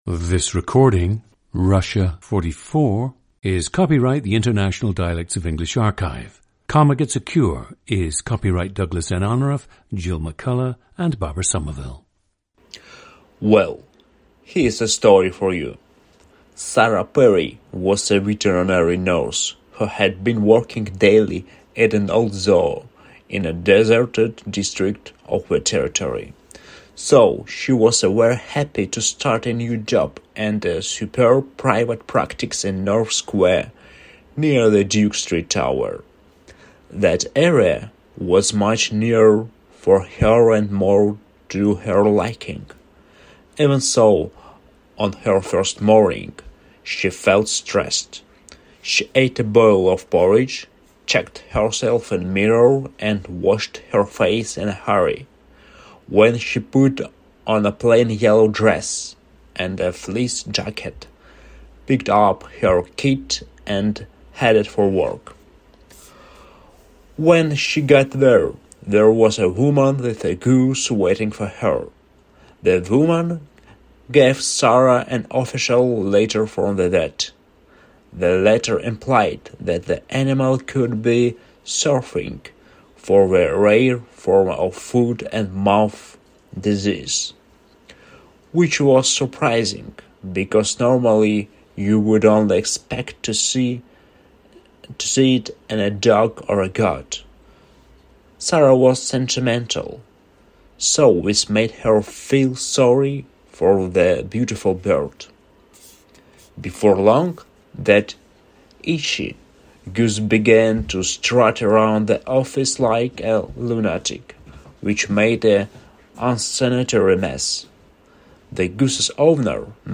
PLACE OF BIRTH: Norilsk, Krasnoyarsk Krai, Russia
GENDER: male
ETHNICITY: Russian/White
The subject had been living in Moscow for about 15 years when this recording was made.
Though the subject grew up in an isolated, northern region of the Asian part of Russia, he says people there don’t sound very different from those in Moscow, at least when speaking English.
The recordings average four minutes in length and feature both the reading of one of two standard passages, and some unscripted speech.